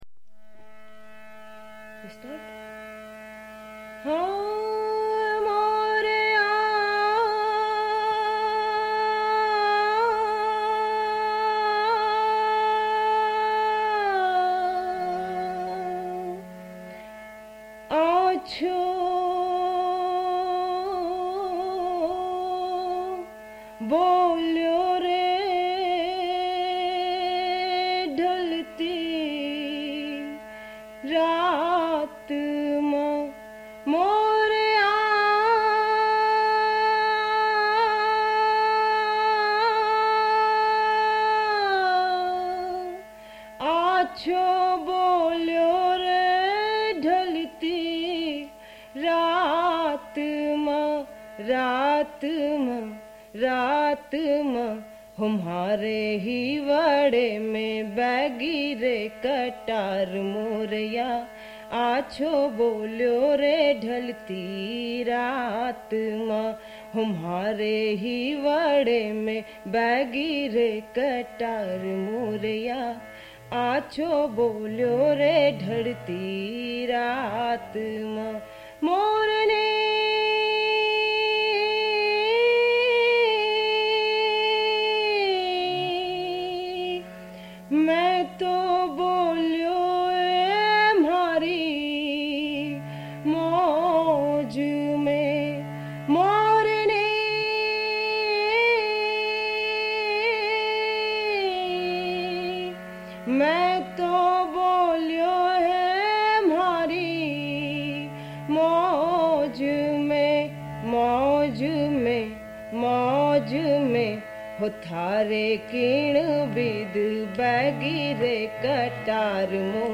Woman singing with harmonium